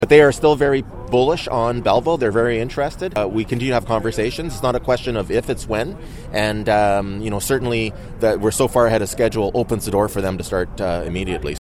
At the Phase 2 ribbon-cutting in November 2020, then Mayor Mitch Panciuk spoke about the membership warehouse club’s interest in Belleville.